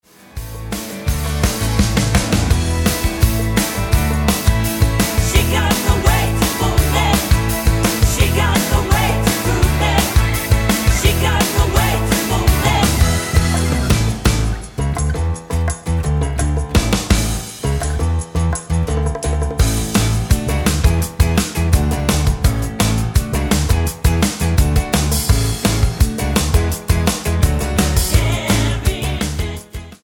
Tonart:E mit Chor